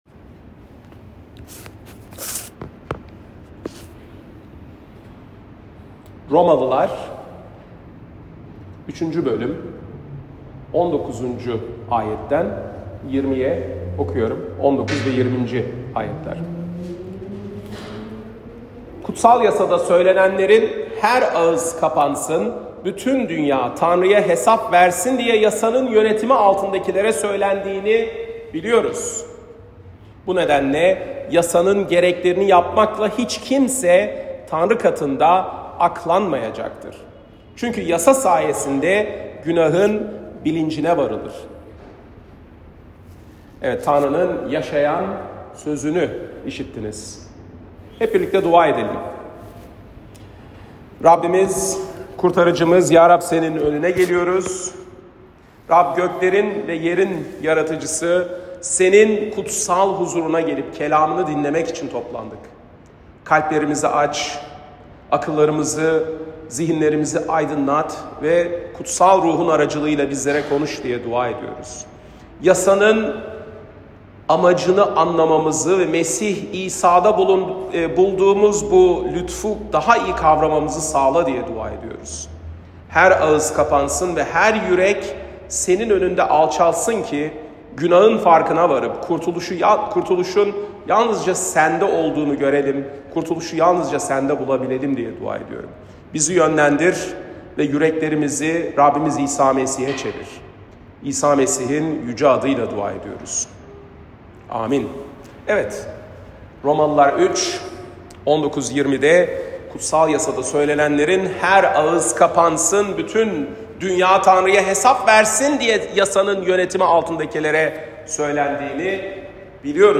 Pazar, 9 Şubat 2025 | Romalılar Vaaz Serisi 2024-26, Vaazlar